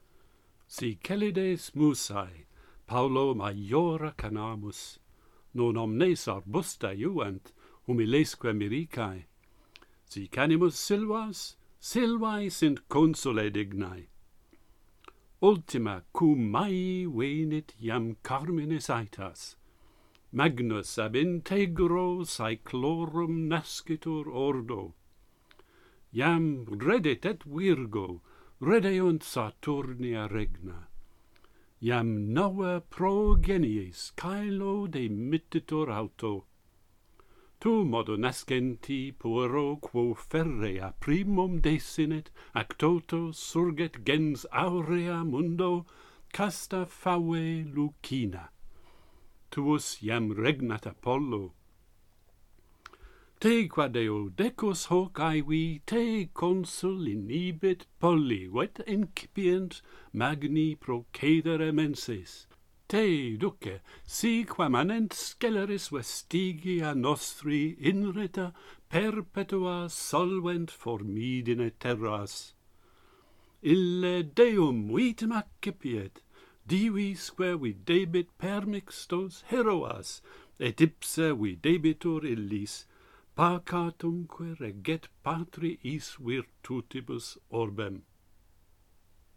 Virgil predicts a forthcoming birth and a new golden age - Pantheon Poets | Latin Poetry Recited and Translated